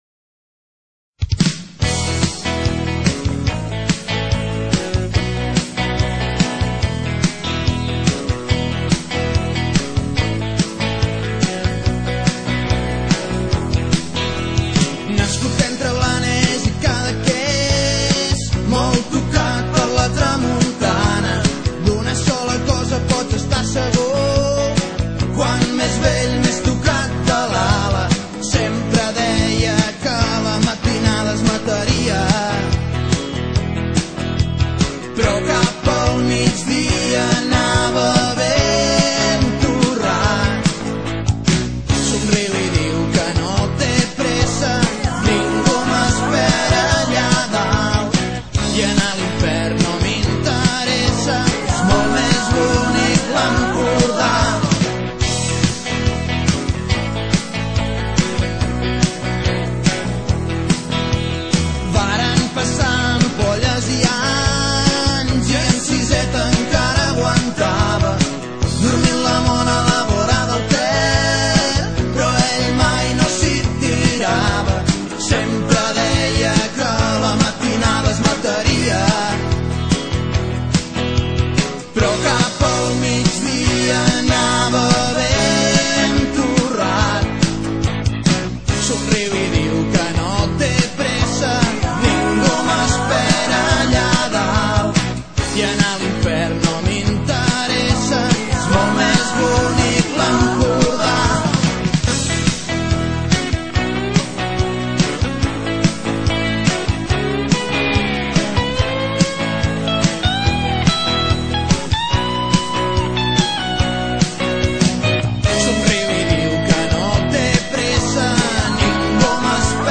bandes de rock